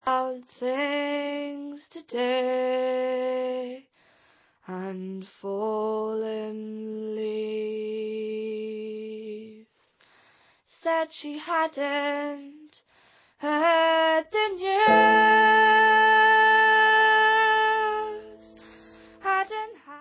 sledovat novinky v oddělení Folk